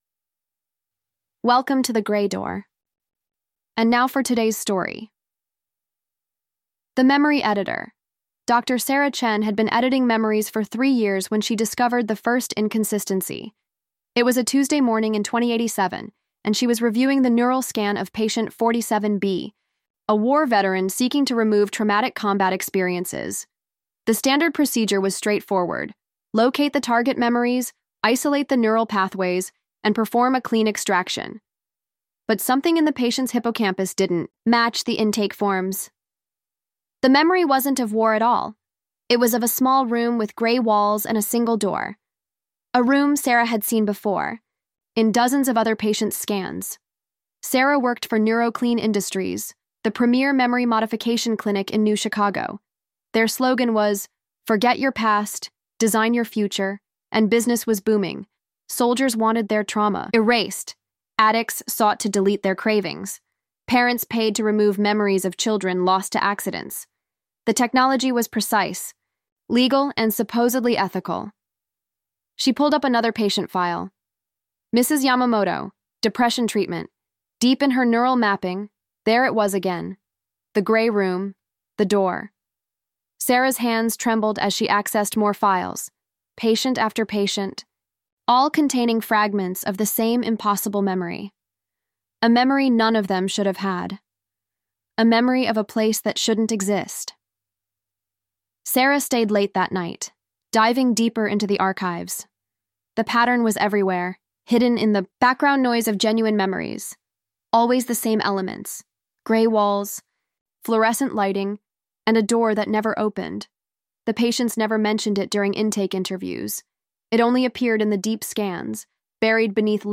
The Gray Door is a storytelling podcast